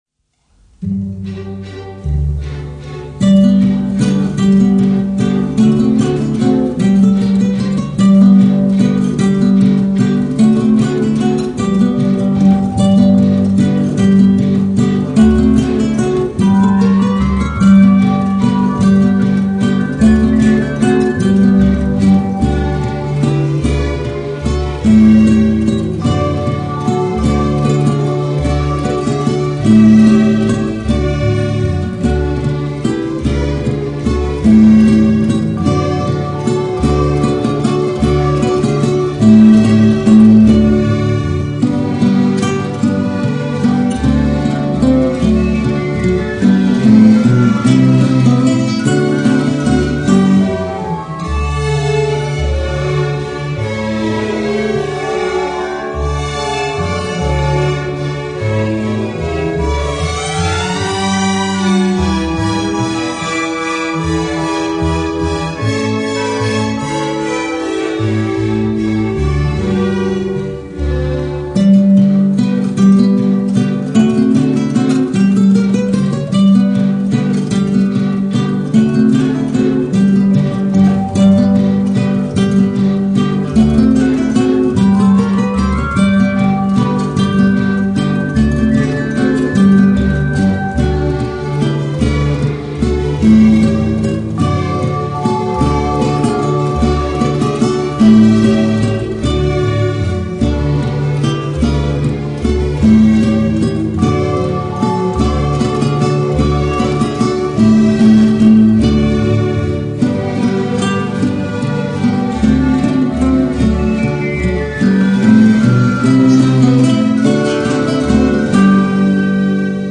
0280-吉他名曲圆舞曲.mp3